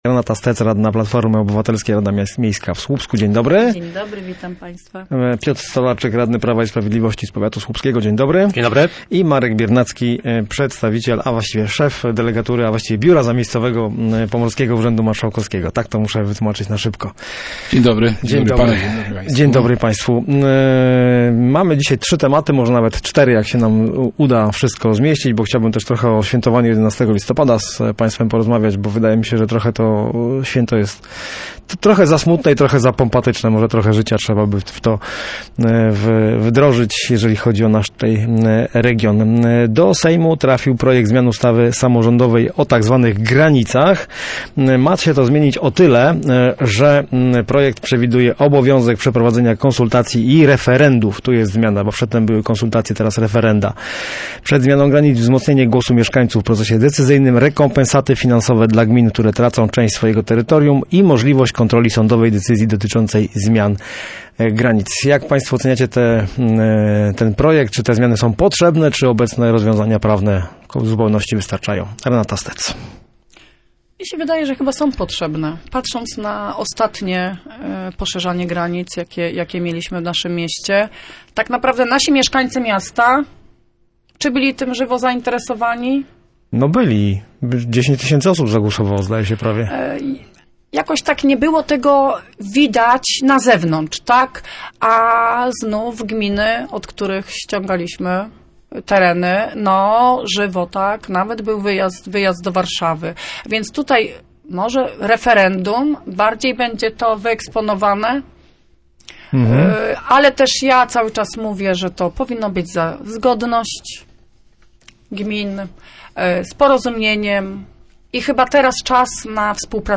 Potrzebna jest zmiana systemu finansowania samorządów, by ucywilizować problem granic administracyjnych – uważają goście miejskiego programu Radia Gdańsk Studio Słupsk 102 FM.
Posłuchaj dyskusji samorządowców: https